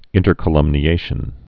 (ĭntər-kə-lŭmnē-āshən)